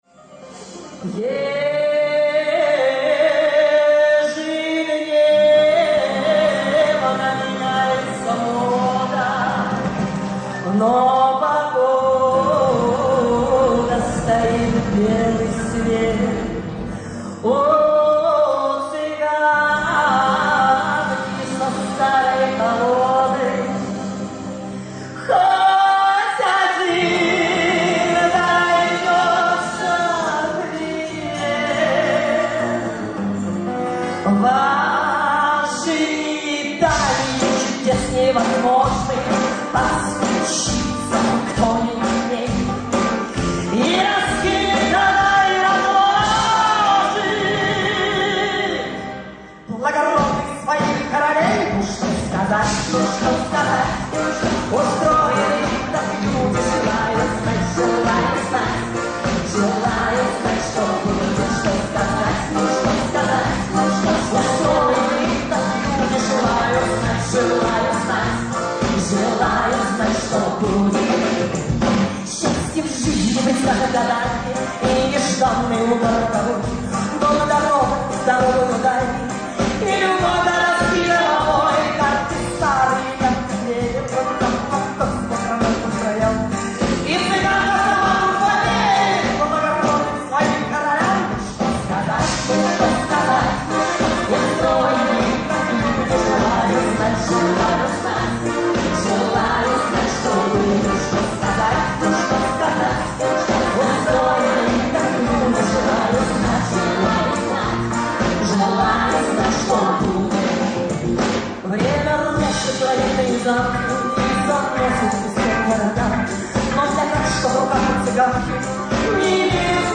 Концертная версия